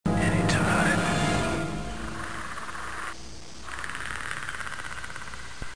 "Anytime ..." The Predator whispers to his prey.